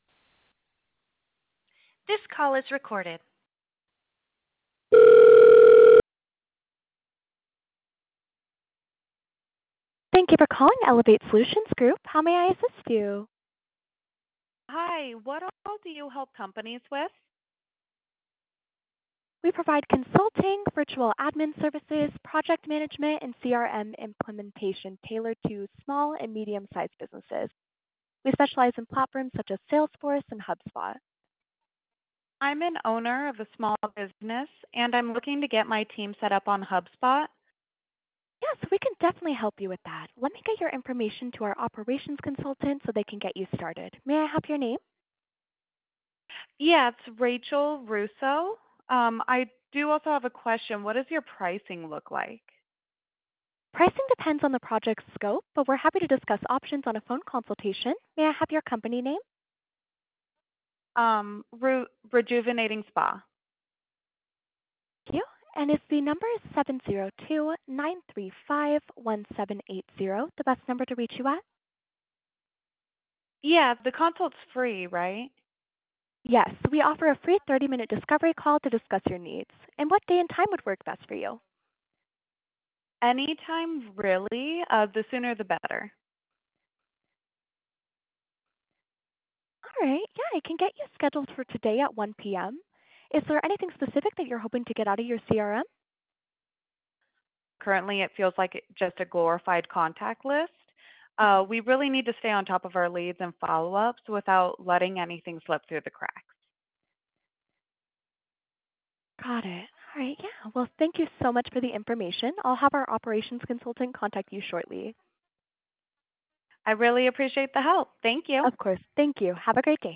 Listen to a demo to hear Abby’s receptionists taking real calls like yours!
Human Receptionist
SAMPLE CALL
Human-Business-Services.wav